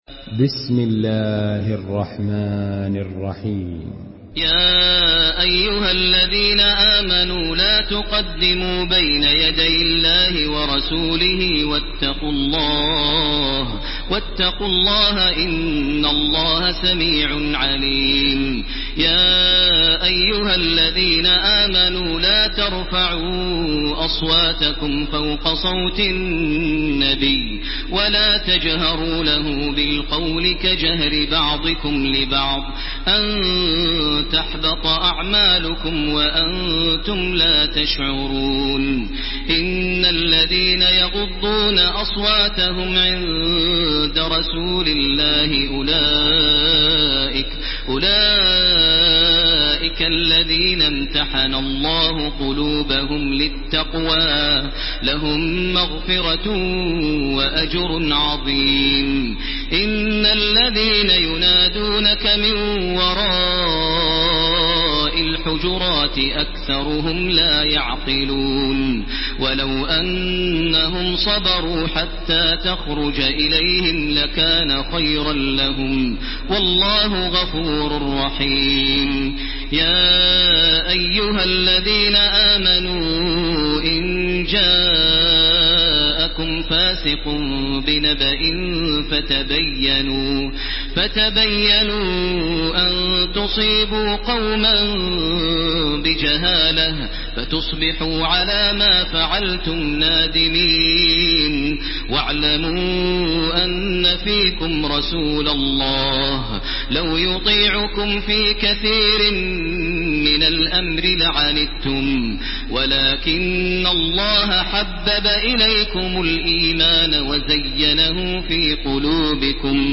Surah Al-Hujurat MP3 by Makkah Taraweeh 1430 in Hafs An Asim narration.
Murattal Hafs An Asim